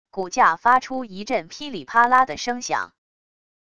骨架发出一阵噼里啪啦的声响wav音频